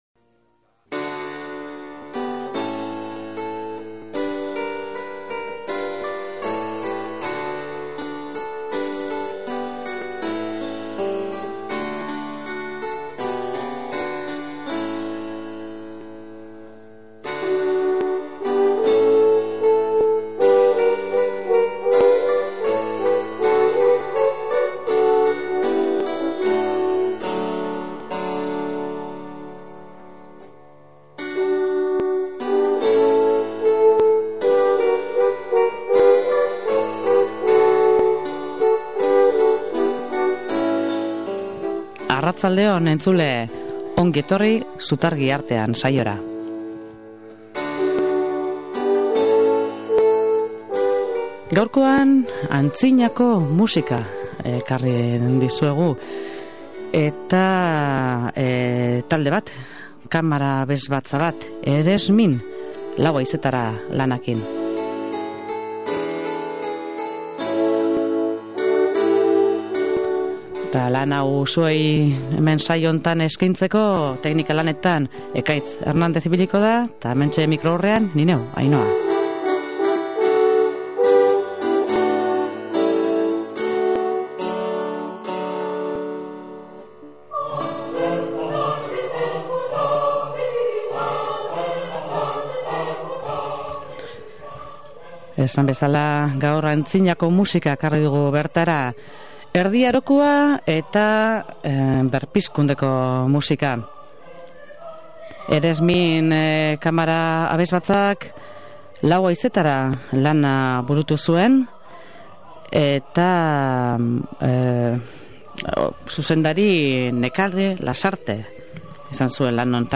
ZUTARGI ARTEAN: Antzinako musika
Bertan Erdi Aroko eta Berpizkunde garaiko Europar herrialde ezberdinetako kantuak entzun ahal izango ditugu: Frantzia, Euskal Herria, Ingalaterra eta Italiakoak, besteak beste.